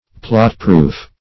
Plot-proof \Plot"-proof`\, a. Secure against harm by plots.